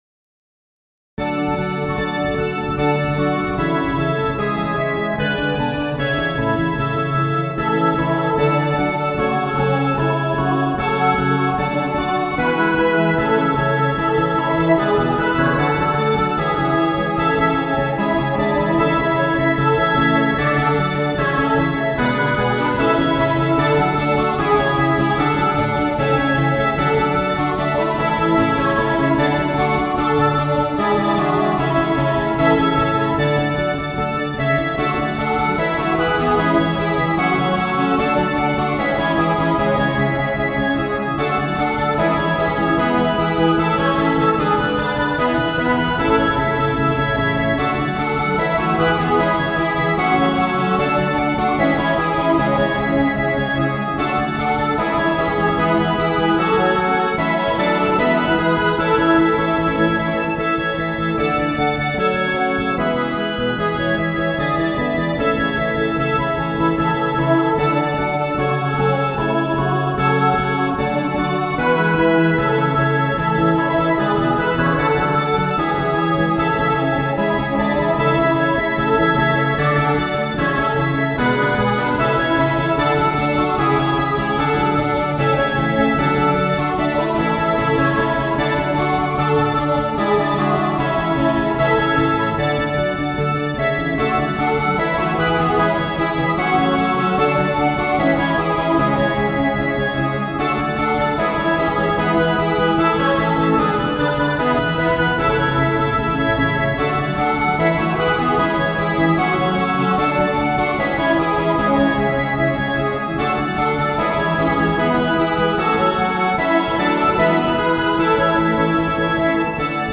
イ長調
曲自体は平準的な曲です。